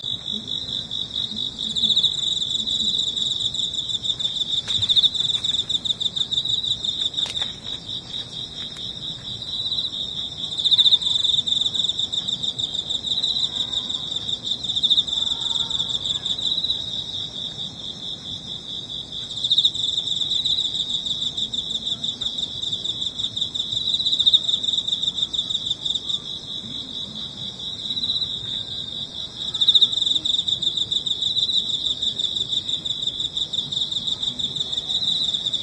秋の兆し１ 鳴く虫（コオロギ）